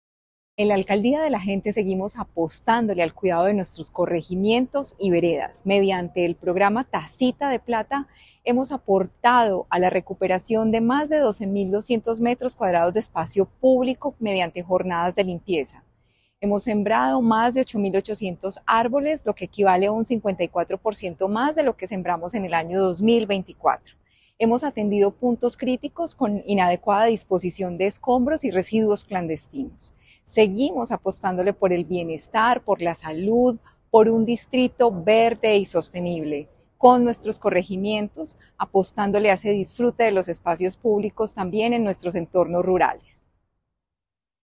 Declaraciones de la secretaria de Medio Ambiente, Marcela Ruiz Saldarriaga Así mismo, la campaña de arborización avanza con paso firme: se han sembrado 8.800 árboles en lo que va del año, frente a 5.610 del año pasado.